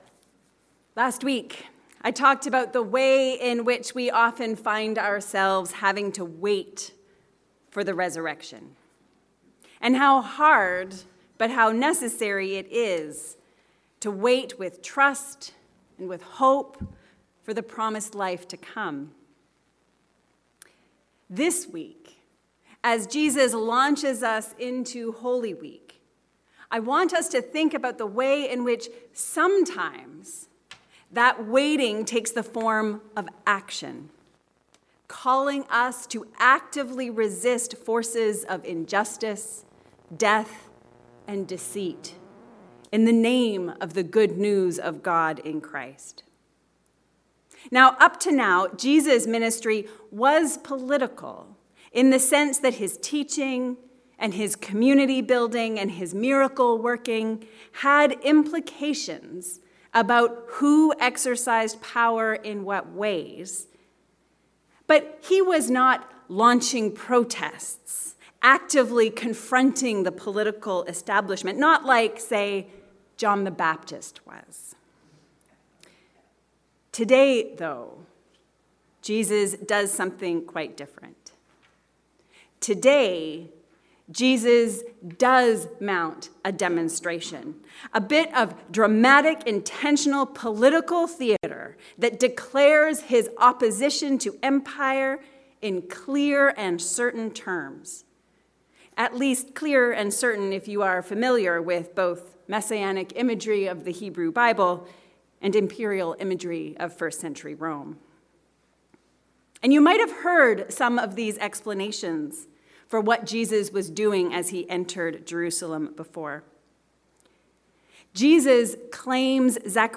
Our king rides a donkey. A Sermon for Palm Sunday